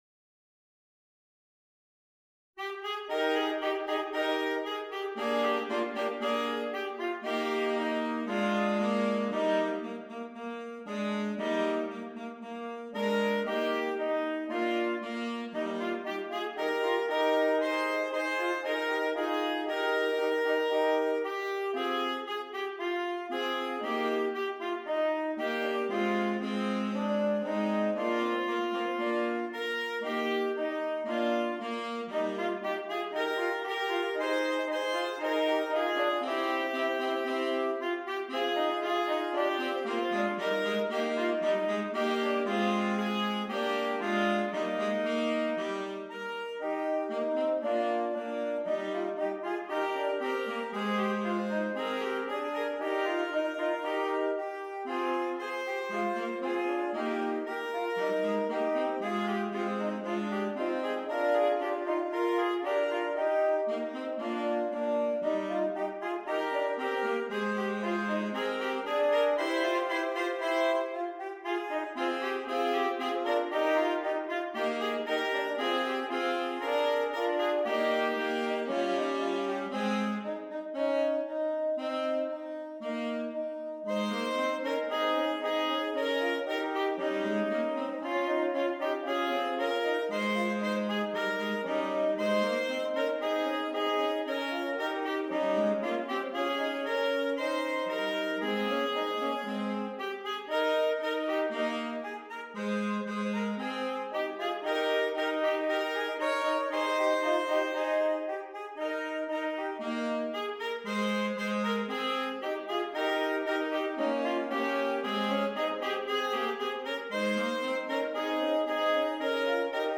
3 Alto Saxophones